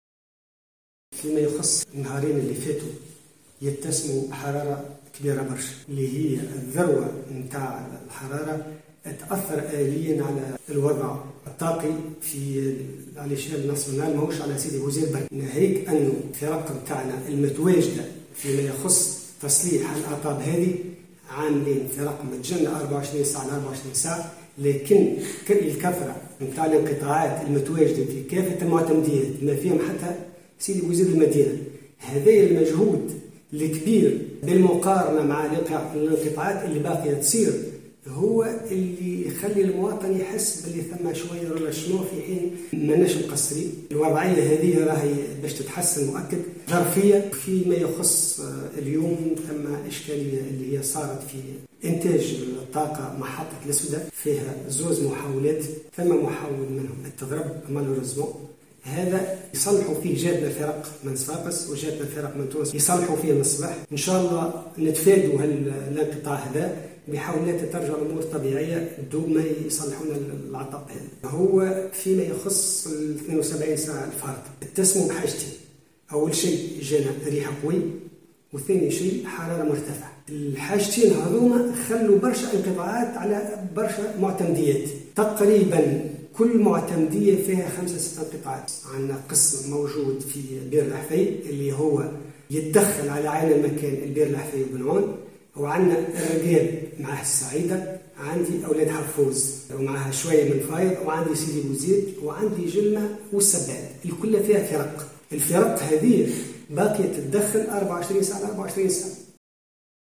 Sidi Bouzid: La STEG dévoile la raison des coupures d’électricité récurrentes (Déclaration)